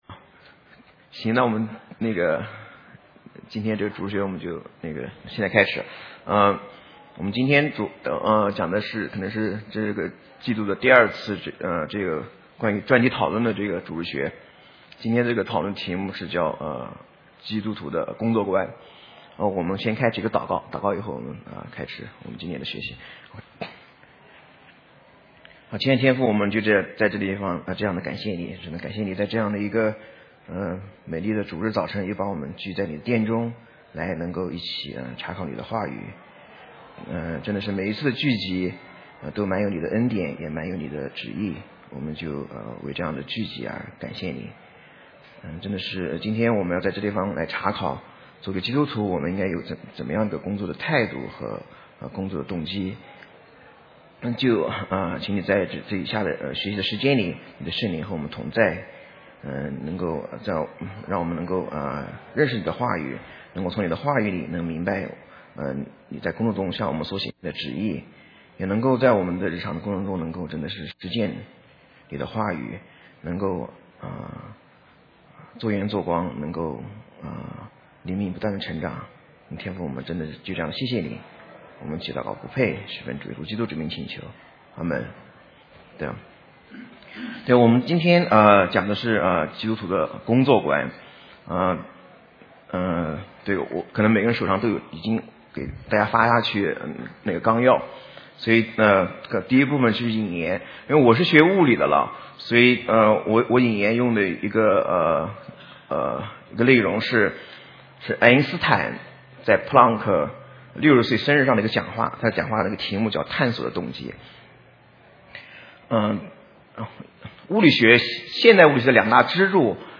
Mandarin Sermons